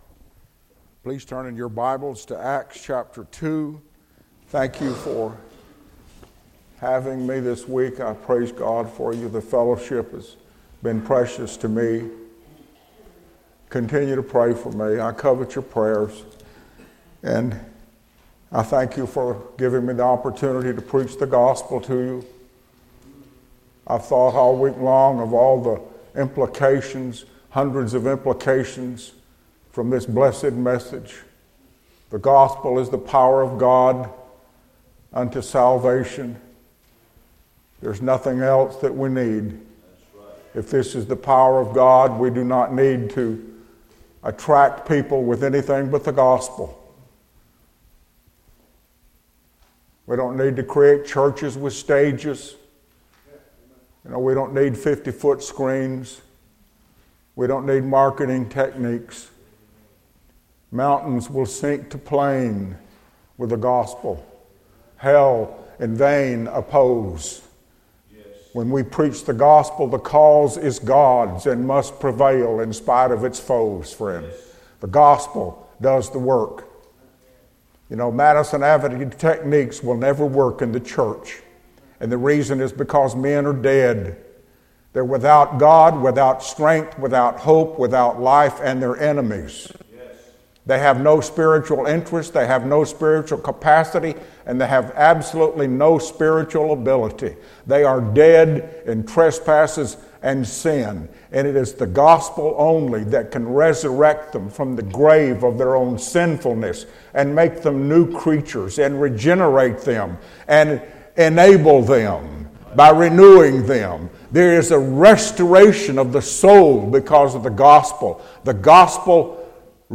Series: 2017 August Conference
Session: Evening Session